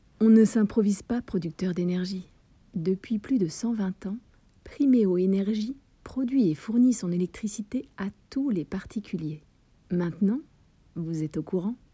Voix off
- Soprano